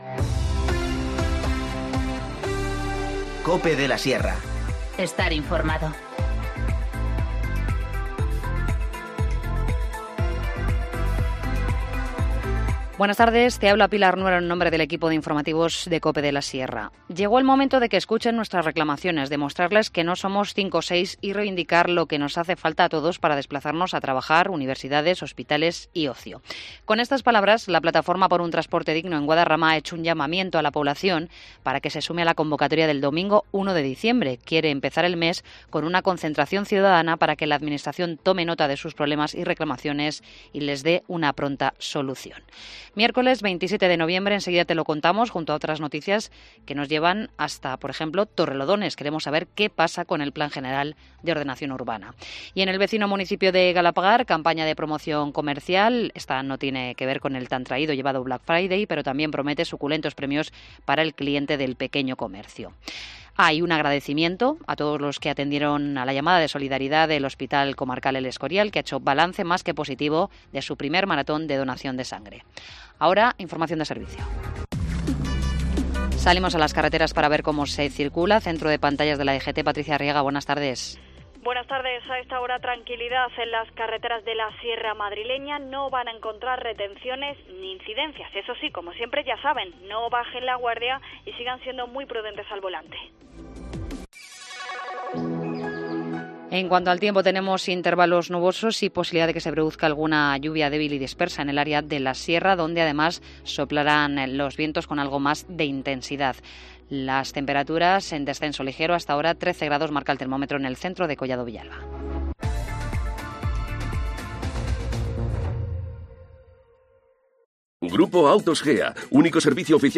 Informativo Mediodía 27 noviembre 14:20h